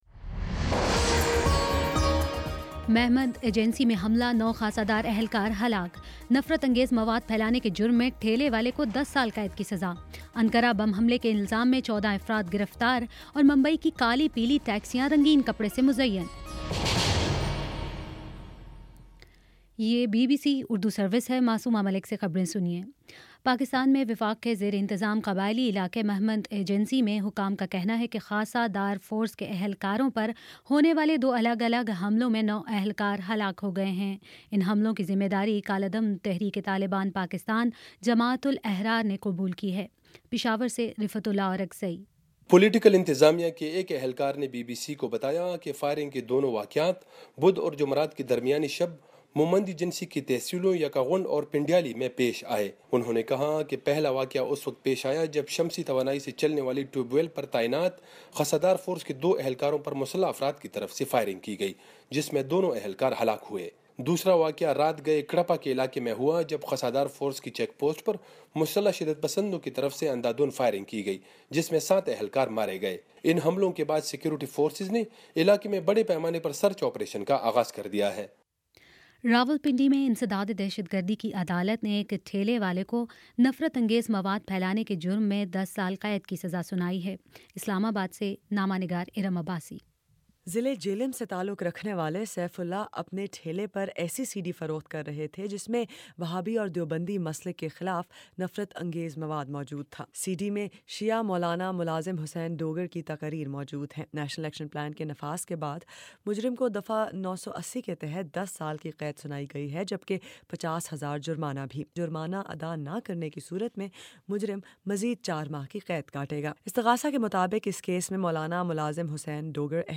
فروری 18 : شام چھ بجے کا نیوز بُلیٹن